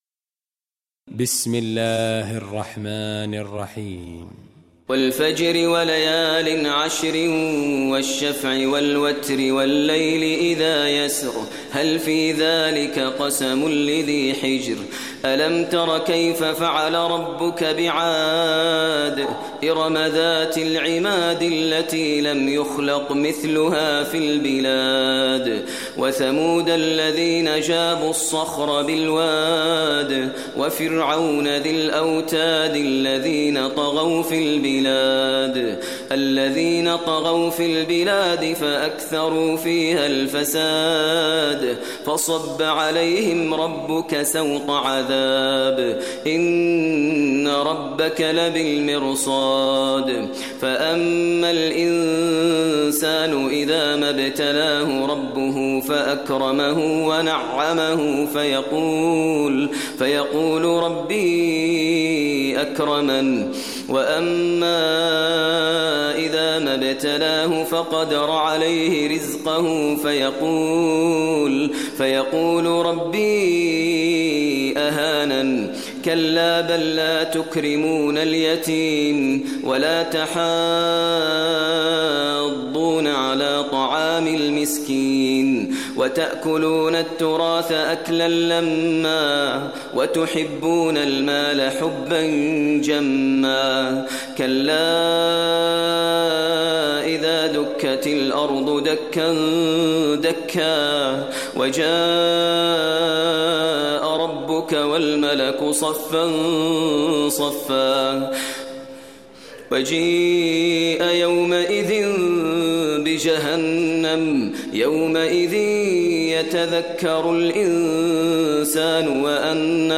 Surah Fajr Recitation by Maher al Mueaqly
Surah Fajr, listen online mp3 tilawat / recitation in Arabic recited by Sheikh Maher al Mueaqly.